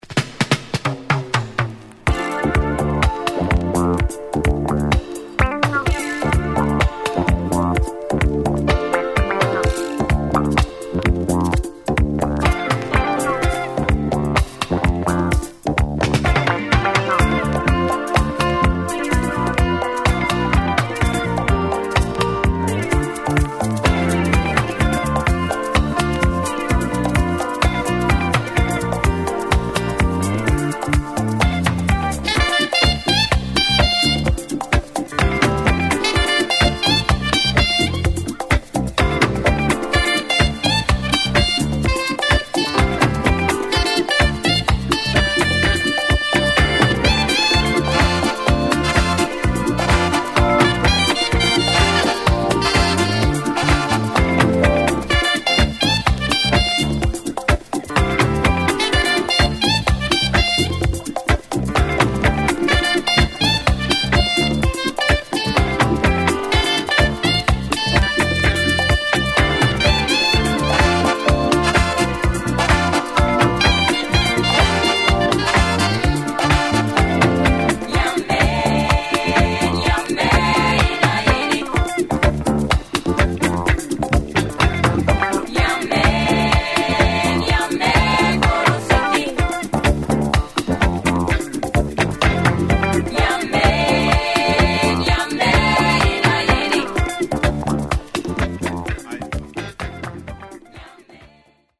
Afro Disco